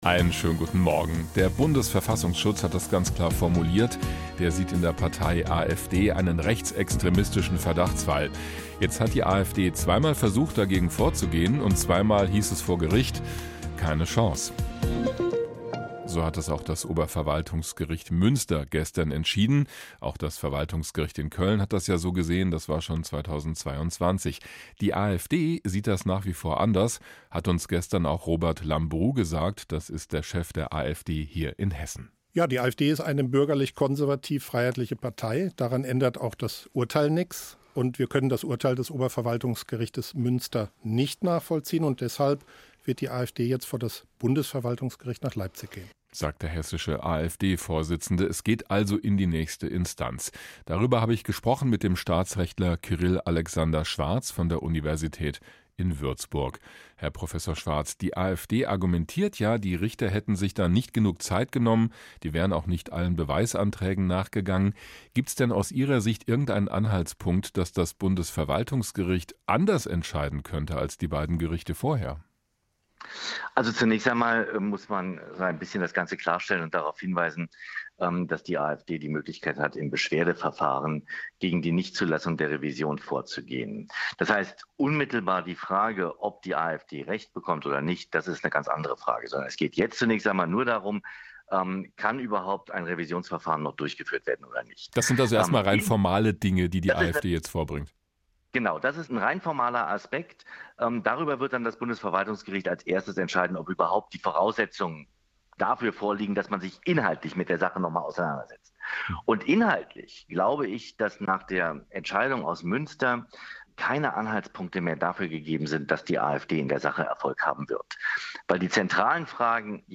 hr-iNFO_Interview.mp3